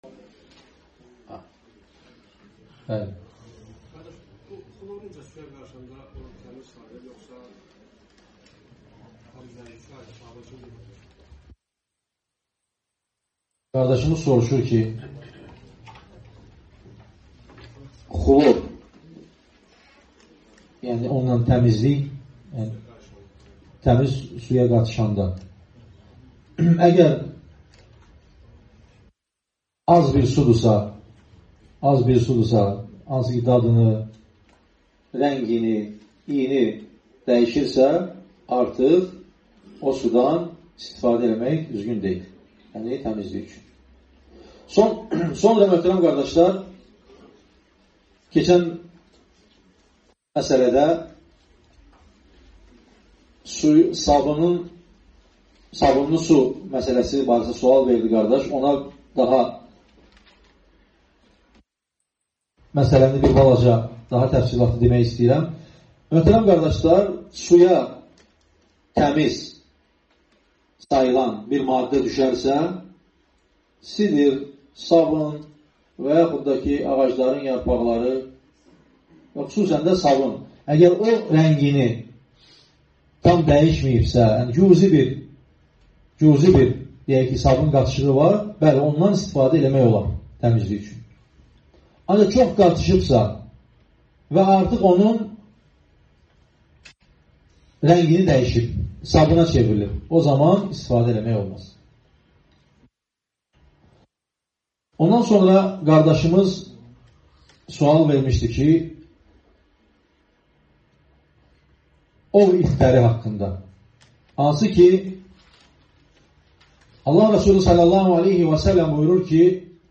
Sual-Cavab (05.01.2025)